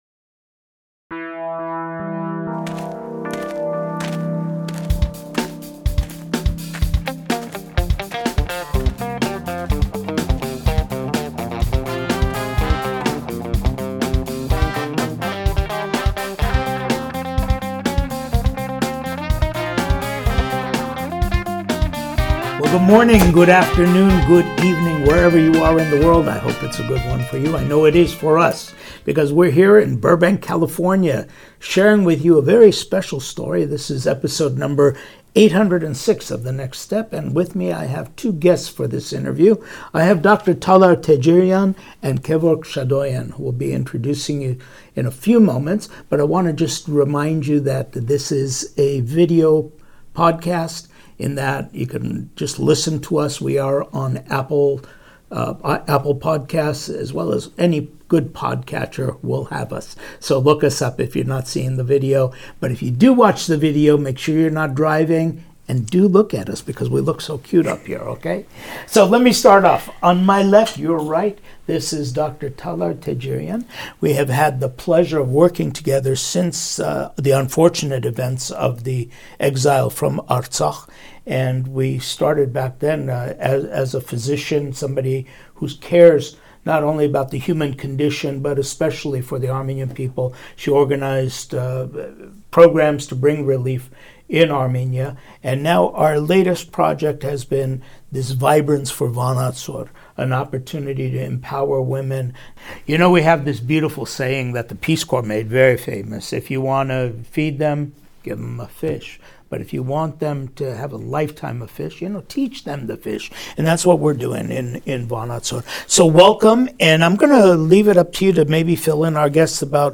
NS807 – Fashion Design Masterclass Interview